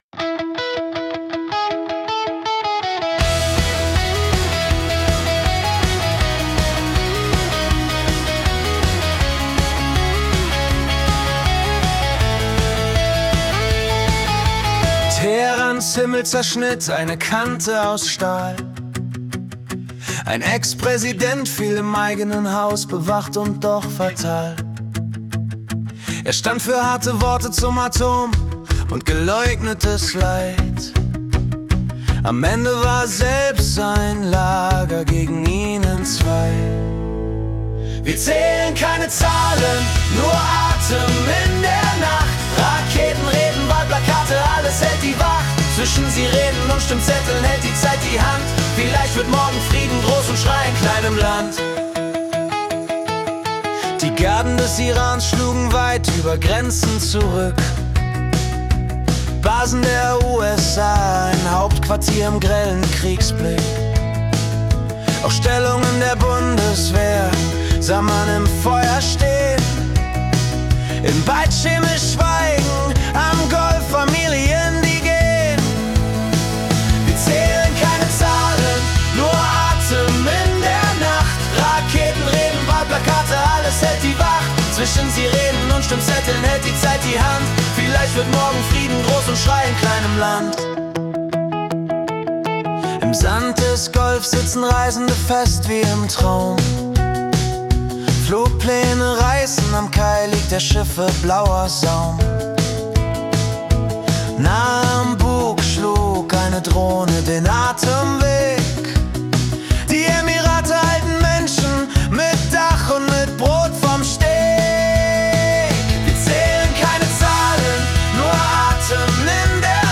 Die Nachrichten vom 2. März 2026 als Singer-Songwriter-Song interpretiert.
Jede Folge verwandelt die letzten 24 Stunden weltweiter Ereignisse in eine originale Singer-Songwriter-Komposition.